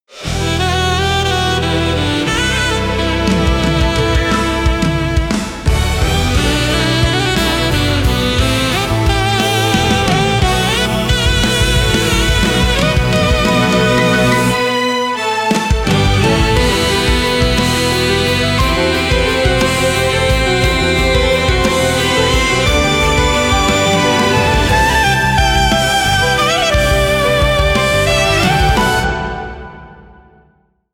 Orchestral Pop117 BPMSax + Piano